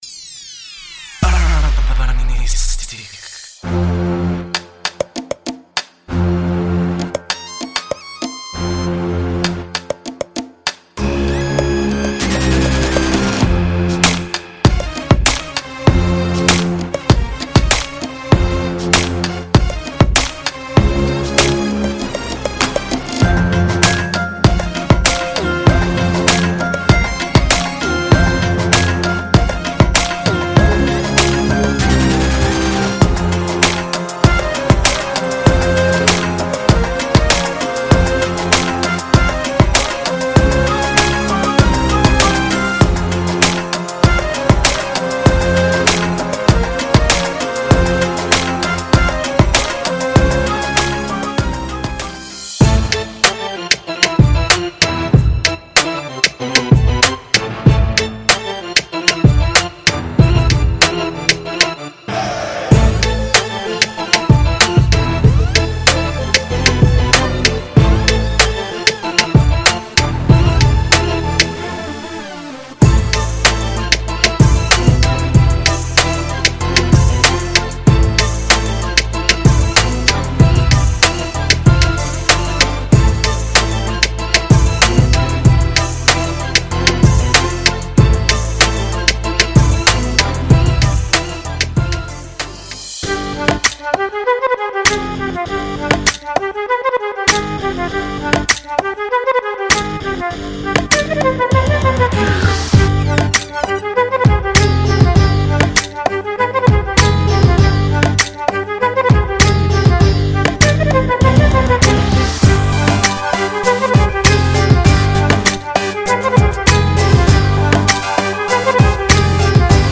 • Over 150 loops per format